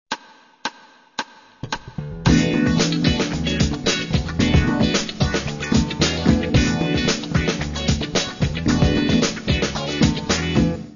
Это уже настоящий аккомпанемент, состоящий из двухтактового аккордового риффа с несколько различными концовками. Основу риффа составляет ми-мажорный нонаккорд (E9), но он активно разнообразится при помощи некоторых ухищрений, которые мы сейчас рассмотрим.
Двухтактовый аккордовый рифф
Опять, первое, что бросается в глаза - это большое количество глухих нот.